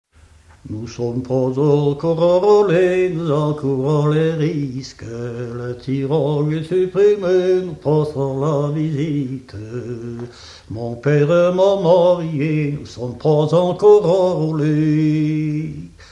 Mémoires et Patrimoines vivants - RaddO est une base de données d'archives iconographiques et sonores.
Chant de conscrits
Genre strophique
Pièce musicale inédite